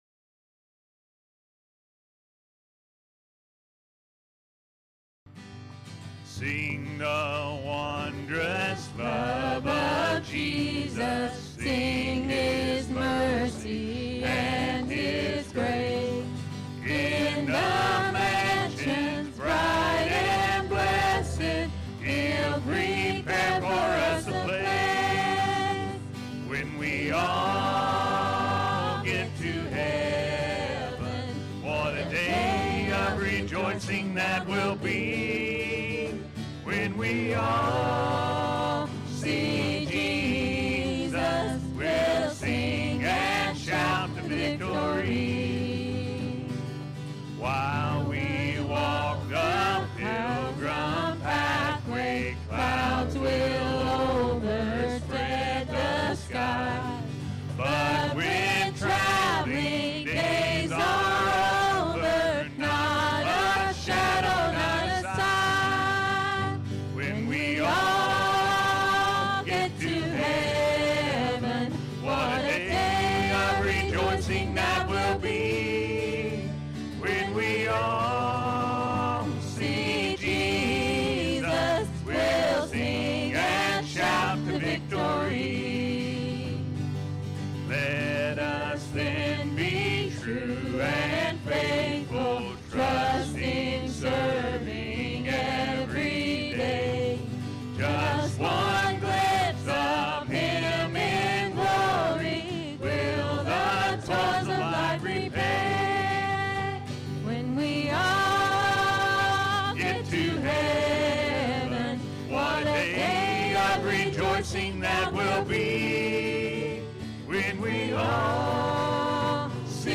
Are You Ready To See Jesus? – A.M. Service